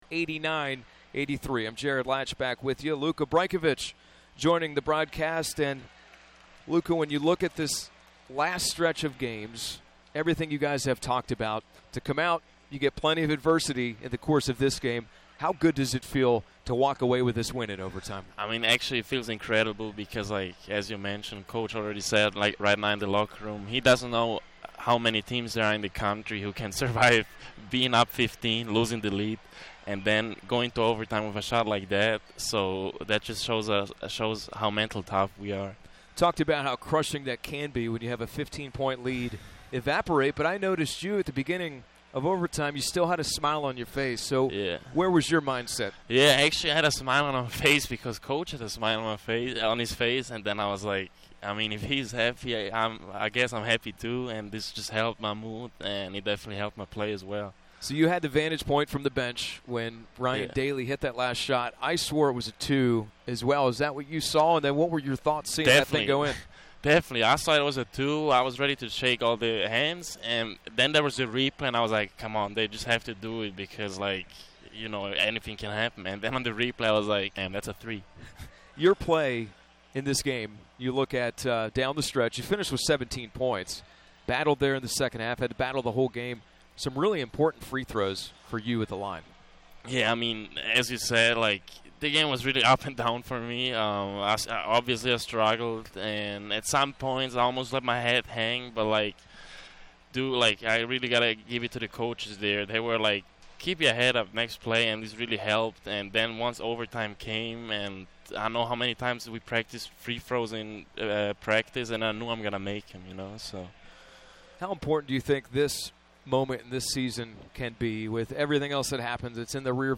Postgame Interview